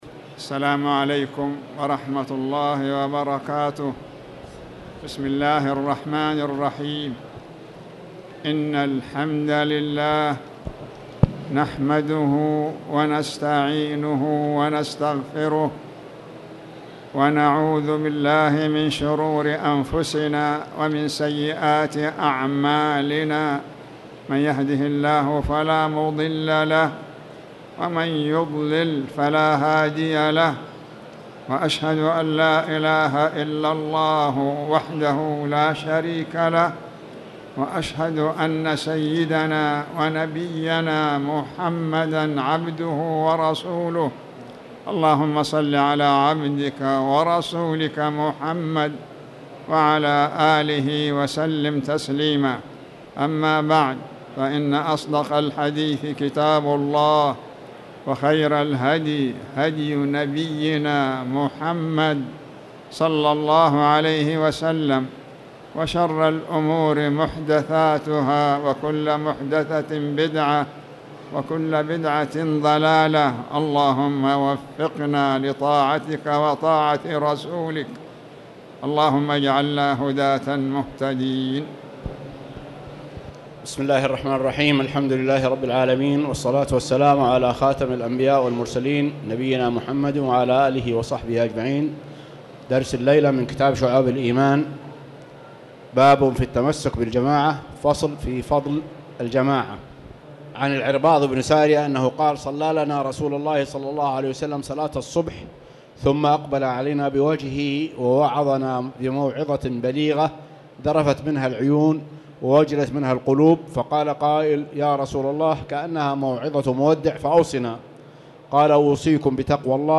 تاريخ النشر ٢٦ ربيع الأول ١٤٣٨ هـ المكان: المسجد الحرام الشيخ